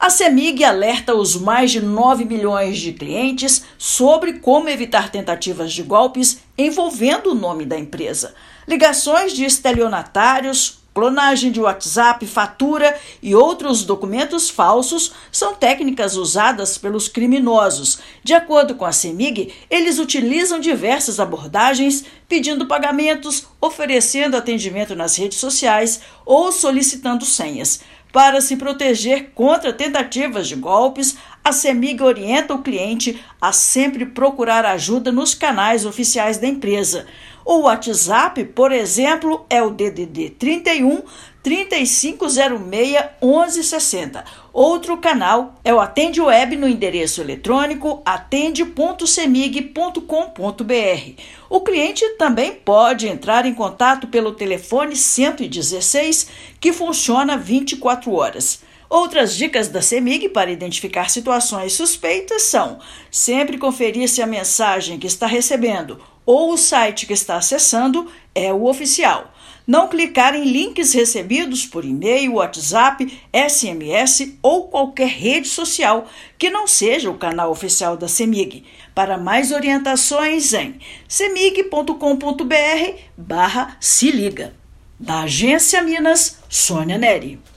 Conheça as técnicas mais comuns utilizadas pelos golpistas e saiba como evitá-las. Ouça matéria de rádio.